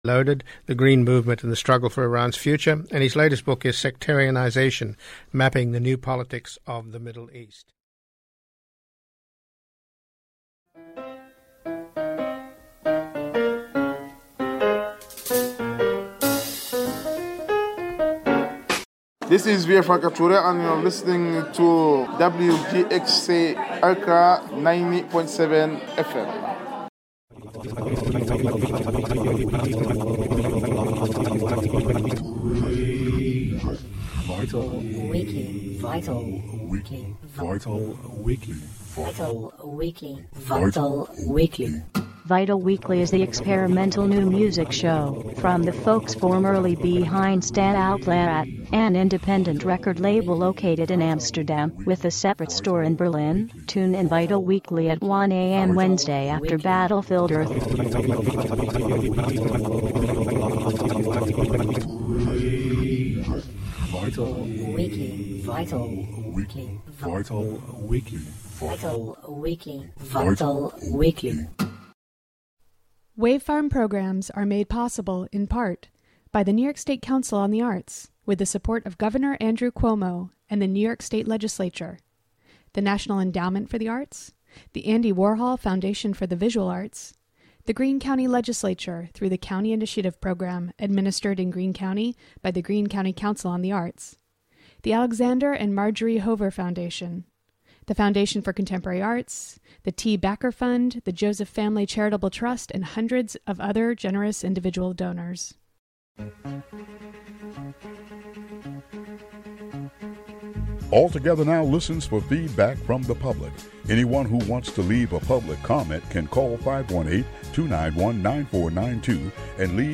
Also hear about today's election. "All Together Now!" is a daily news show brought to you by WGXC-FM in Greene and Columbia counties.
"All Together Now!" features local and regional news, weather updates, feature segments, and newsmaker interviews.